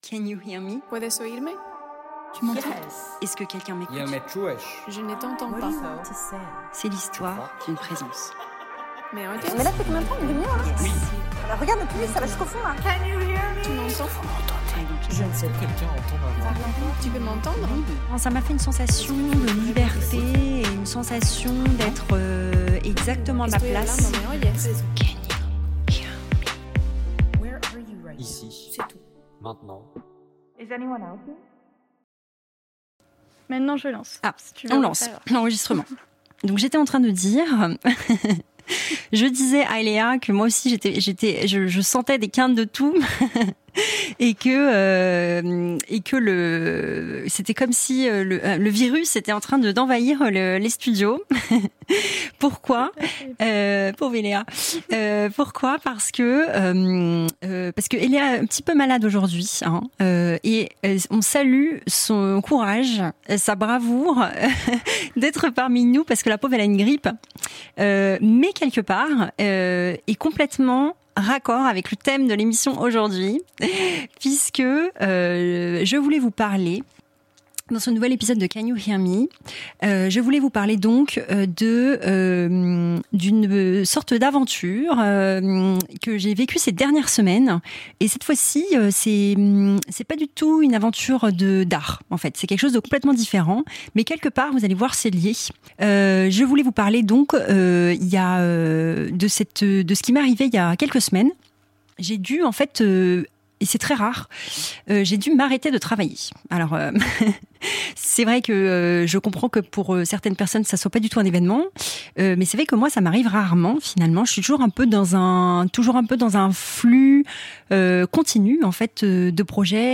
This episode is quieter. Less movement, more listening.
Episode 4 • A pause (French) Episode 4 • A pause (French) Partager Type Création sonore Découvertes musicales Culture lundi 15 décembre 2025 Lire Pause Télécharger In this episode, I talk about something new for me.